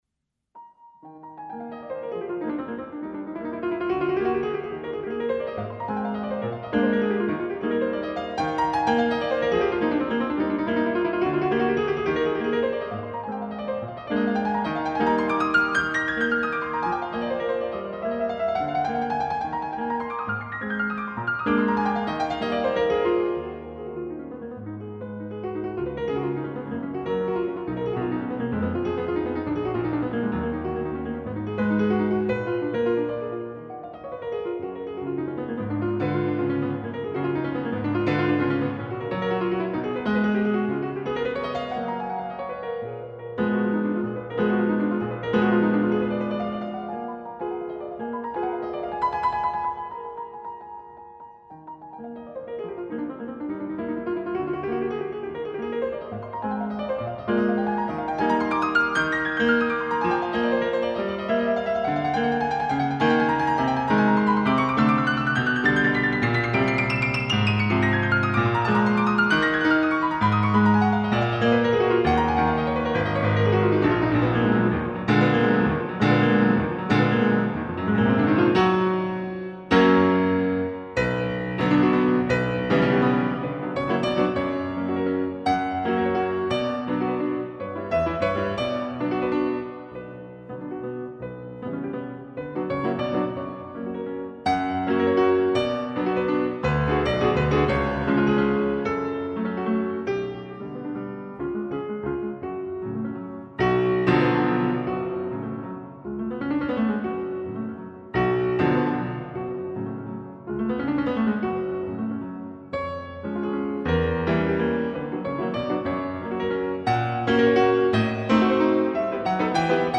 Konzertflügel:
aus dem Schloss Bad Homburg.
in C-Moll ~ D 899/1
Authentic Classical Concerts